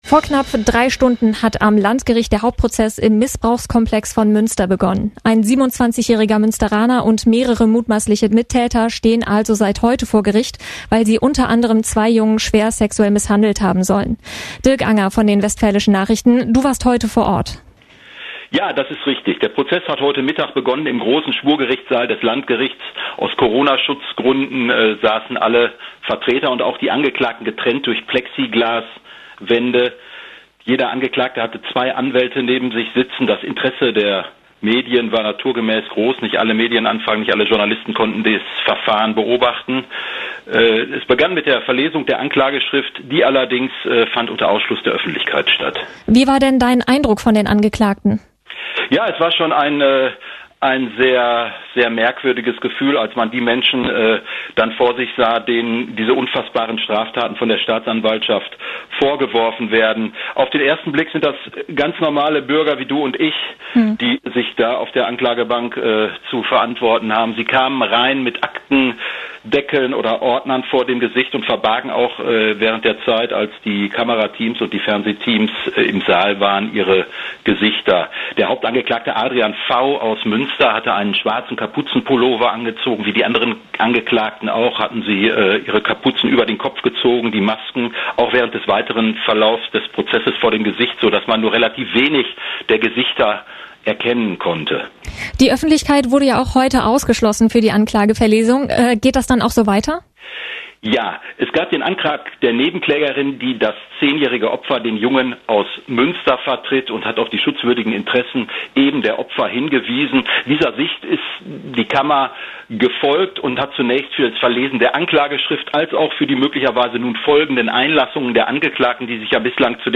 mitschnitt_misssbrauchsprozess.mp3